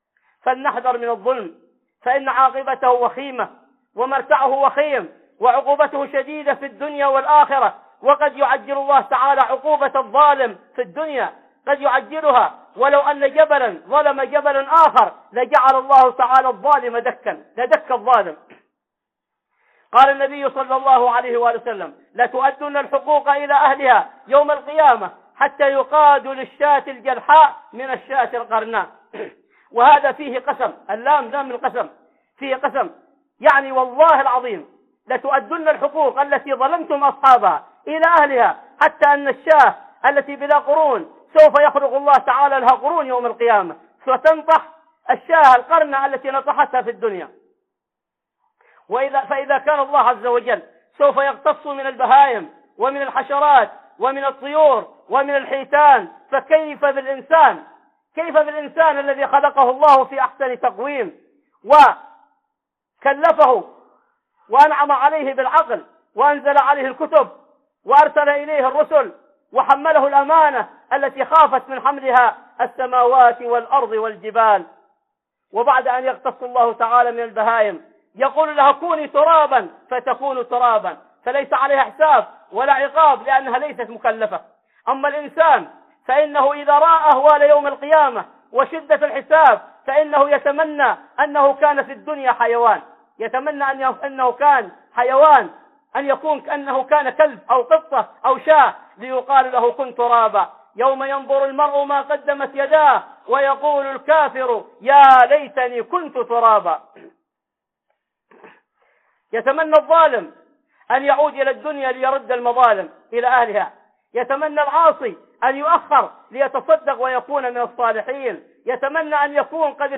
موعظة مؤثرة تبين خطورة الظلم وعقوبة الظالمين في الدنيا والآخرة.